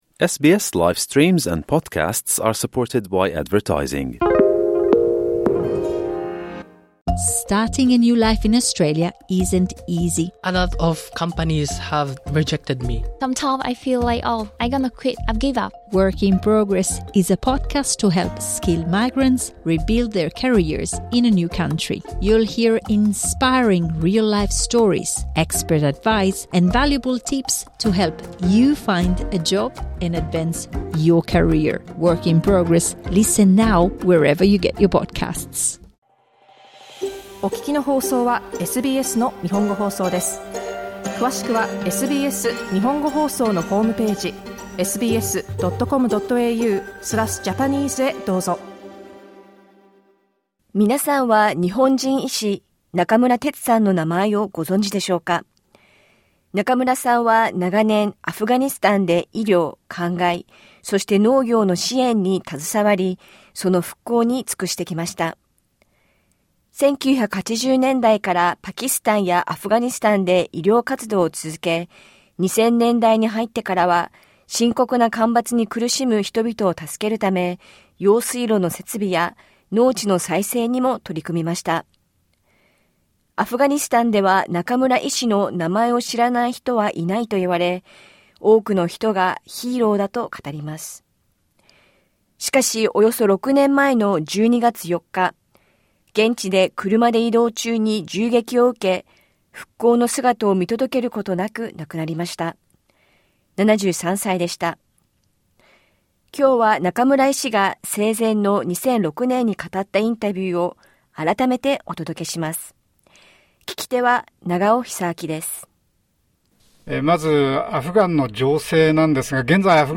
Here is an interview Dr. Nakamura gave back in 2006.